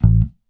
Bass_Stab_07.wav